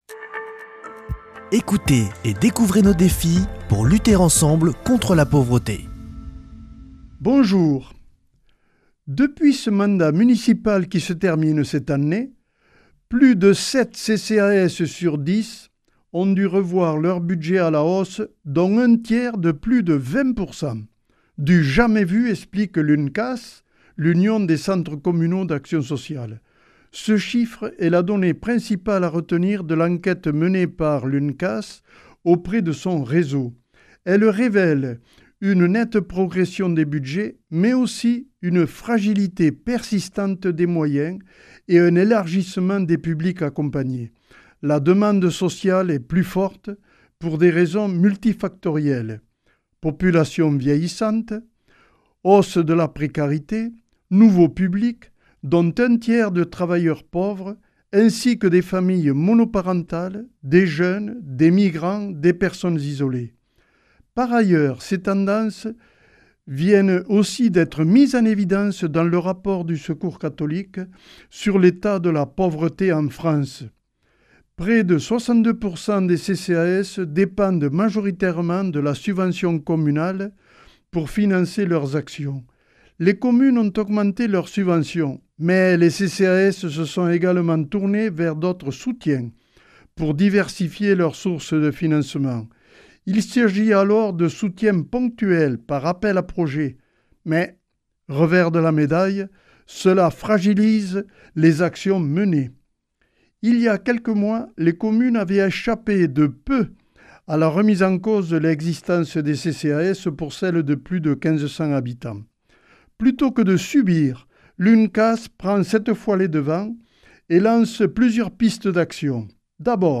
mardi 10 mars 2026 Chronique du Secours Catholique Durée 3 min